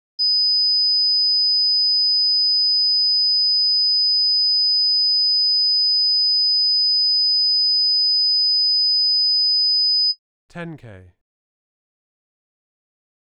52 - 5kHz.wav